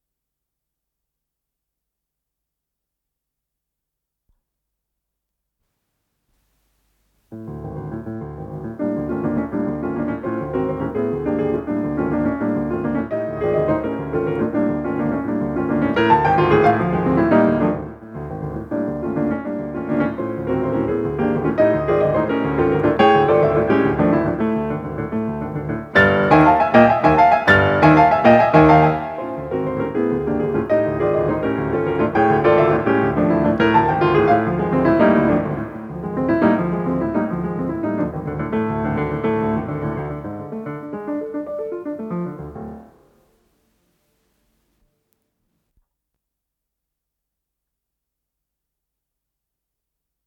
с профессиональной магнитной ленты
фортепиано